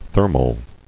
[ther·mal]